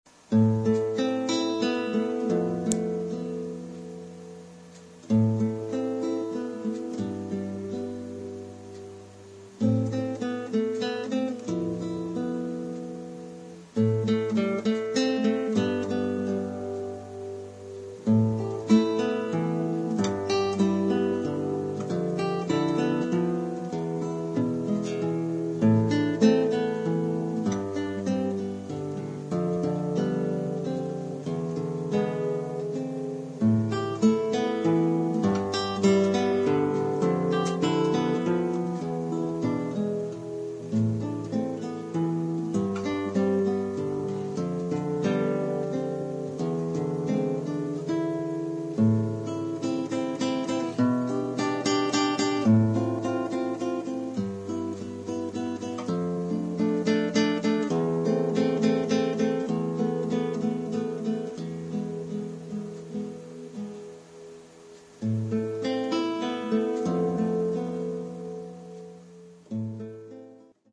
1 titre, guitare solo : partie de guitare
Oeuvre pour guitare solo.